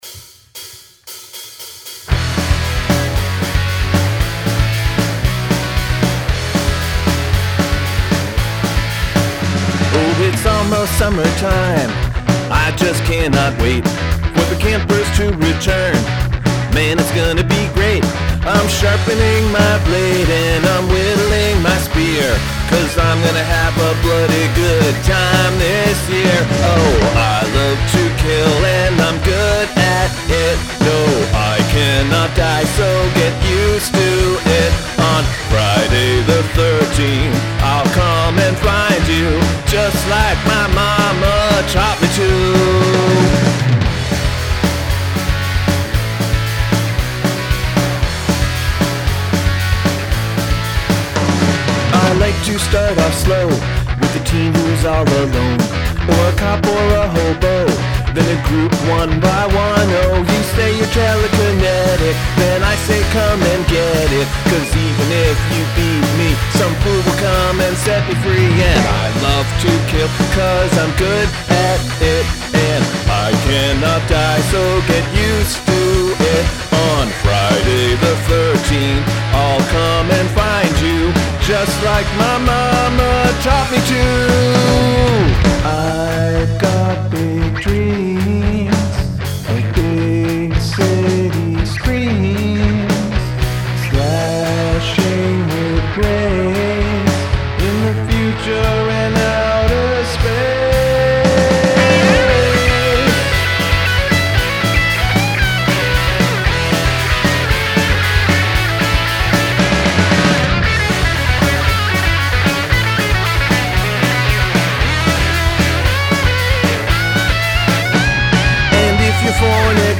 Challenge: happy lyrics, upbeat music
:!: :!: :!: :!: Strong guitar. Scary cheery vibes.
This is a decent slice of technically proficient power pop.